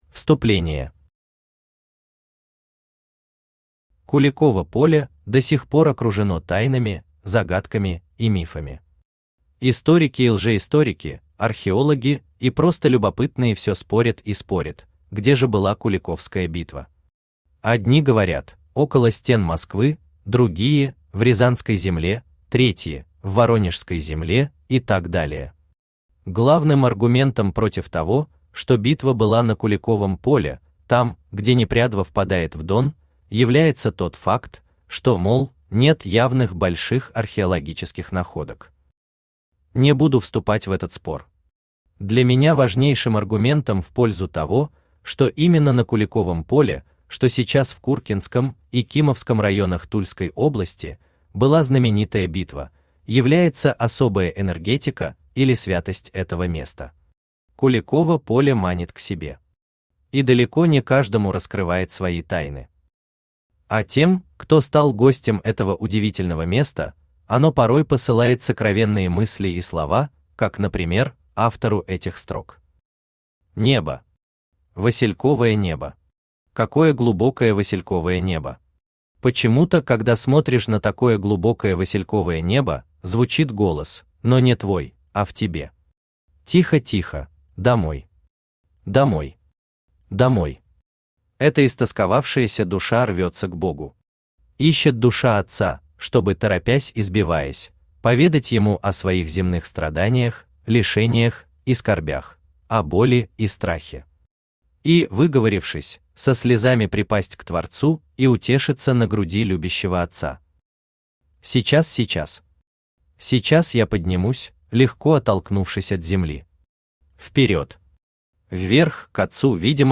Aудиокнига Мифы и были Куликова поля Автор Игорь Аркадьевич Родинков Читает аудиокнигу Авточтец ЛитРес.